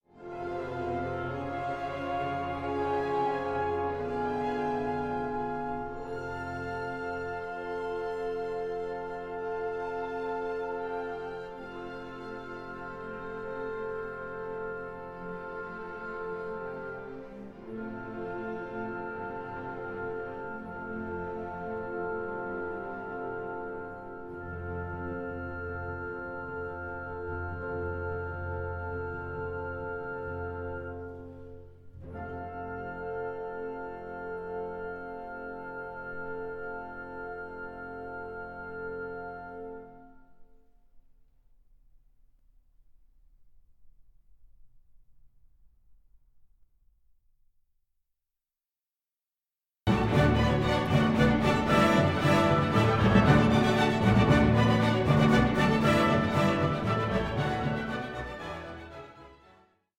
Symphony No. 2 in D Major, Op. 73